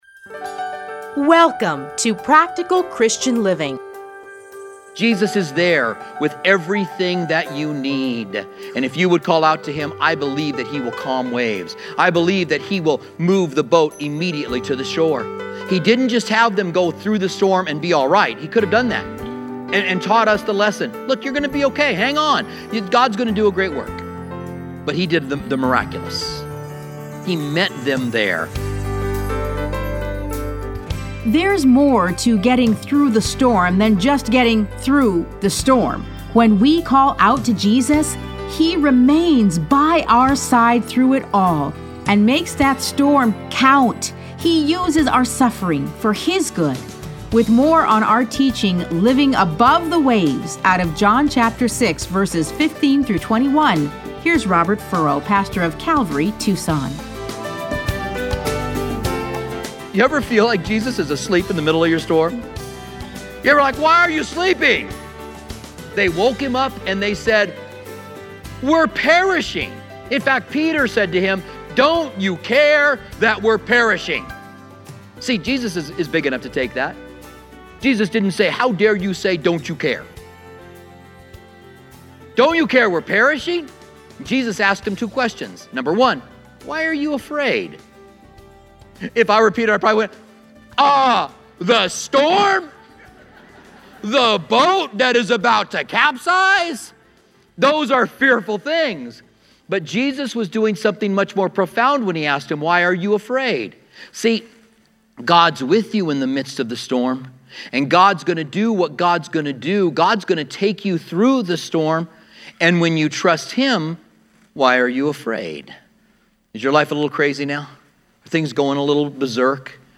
Listen to a teaching from John 6:15-21.